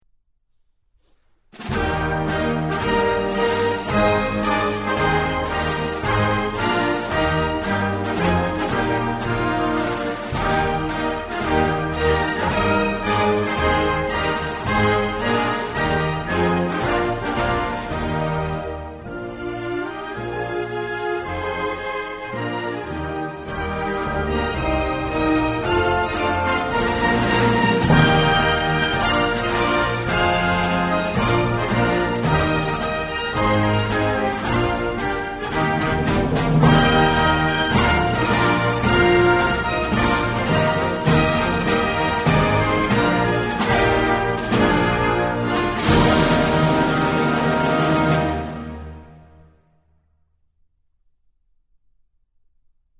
Tags: Historical Asia National Anthem China Japan